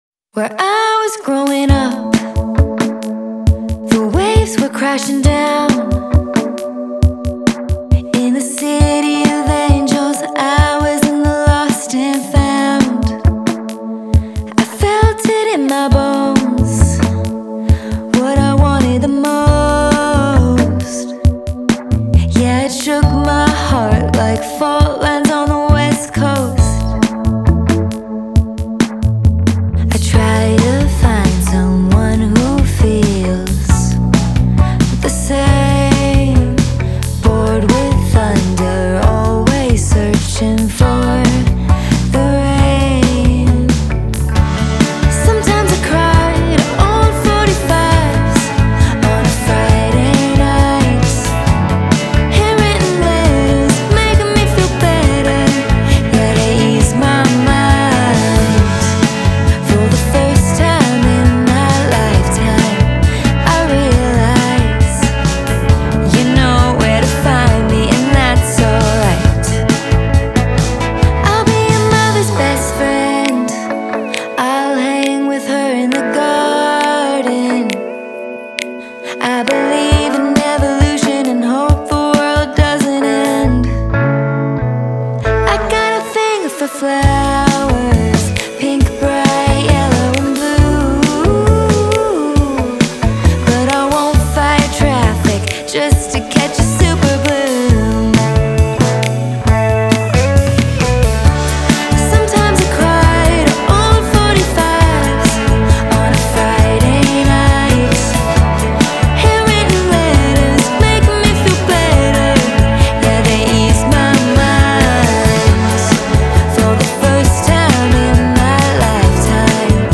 “Joyous and energetic” Euphoria
“Wonderfully warm & uplifting pop” Mystic Sons